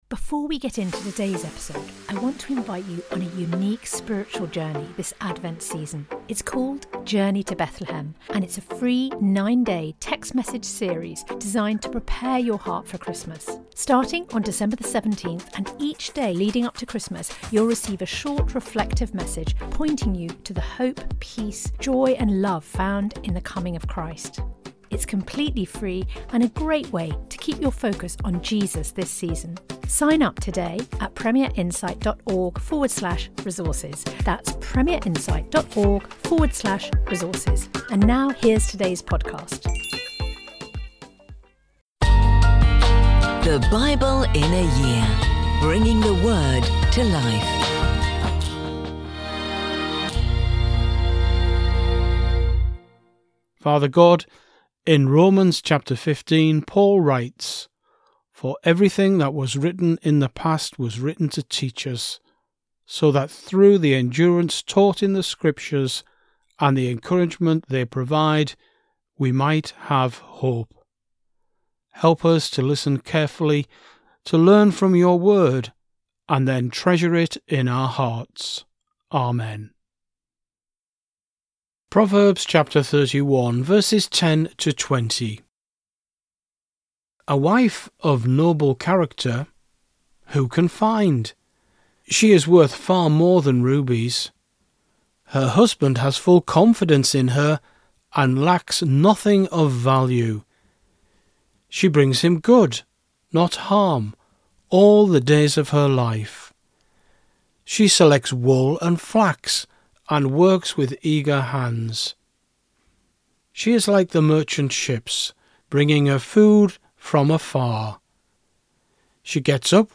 Every day throughout the year we'll be bringing you an audio scripture reading from the Old and New Testament.